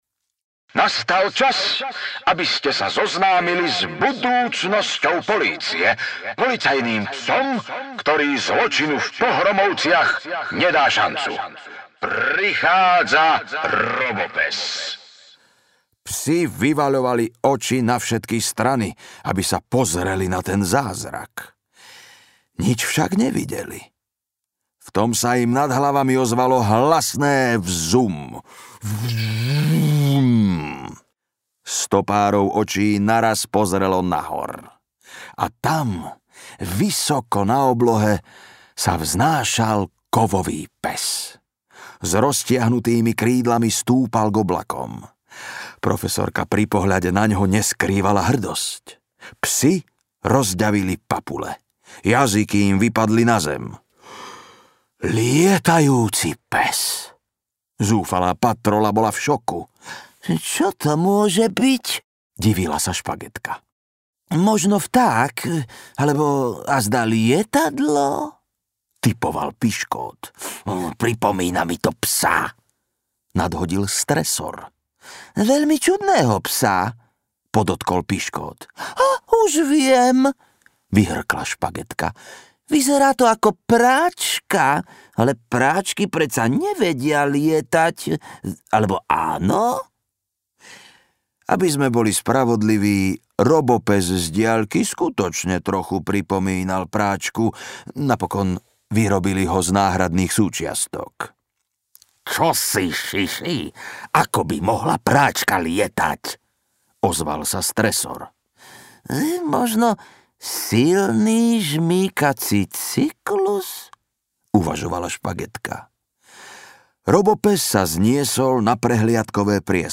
Robopes audiokniha
Ukázka z knihy